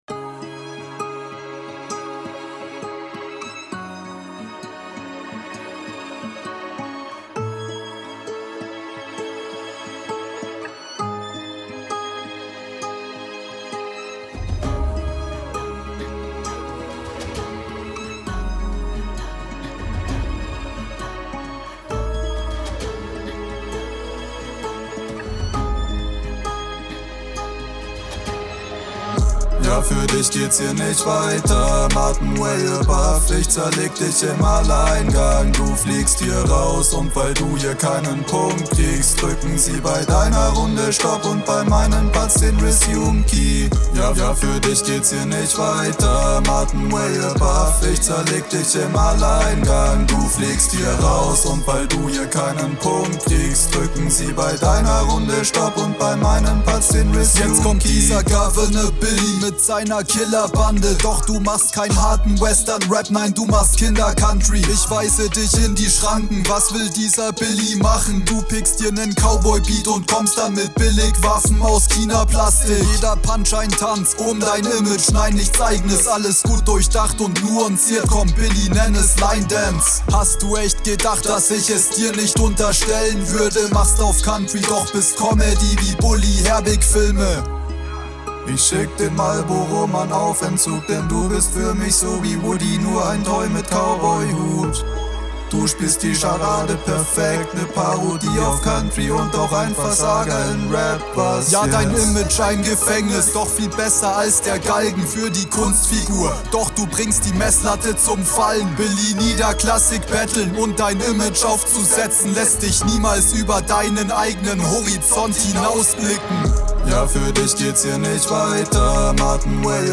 Deutlicher mehr dein Beat, schöne Harmonien in der Hook.
Gesangspassage vom Anfang könnte mit nem besseren Mix cool kommen.